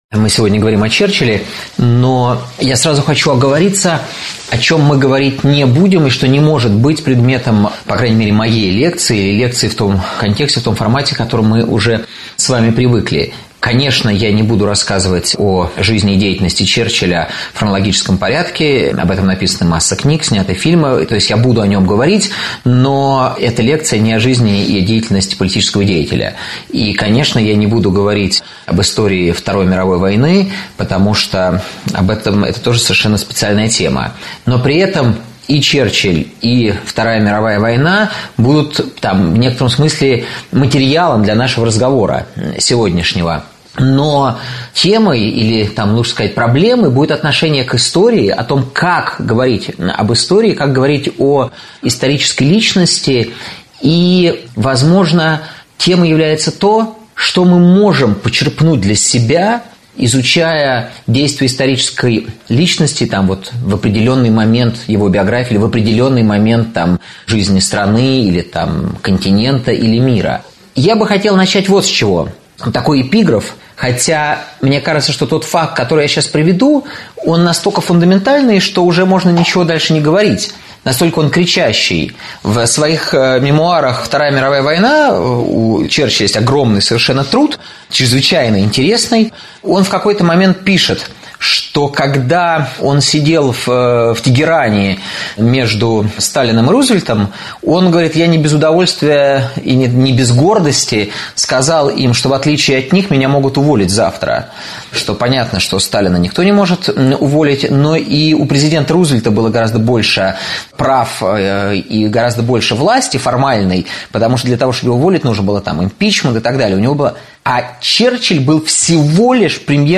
Аудиокнига Уинстон Черчилль: величие человека и достоинство политика | Библиотека аудиокниг